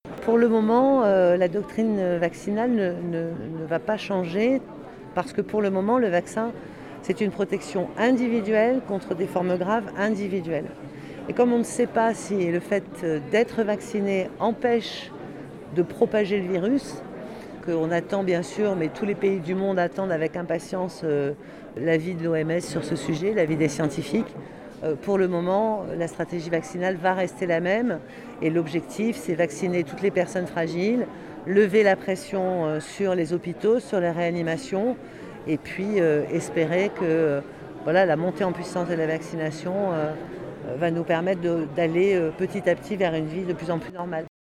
)] Interrogée par les étudiants sur la vaccination, la ministre répond: «Pour le moment, la doctrine vaccinale ne va pas changer parce le vaccin est une protection individuelle, contre des formes graves individuelles.